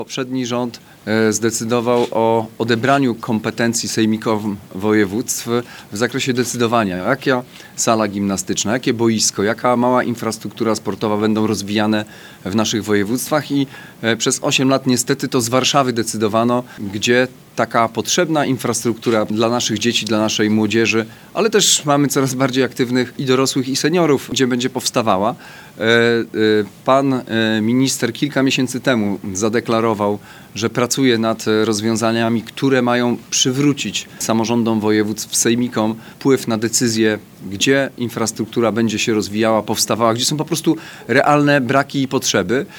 Marszałek Olgierd Geblewicz w trakcie konferencji prasowej podkreślał, że to krok w dobrym kierunku, bo właśnie w regionach wiedza o potrzebach jest najbardziej znana.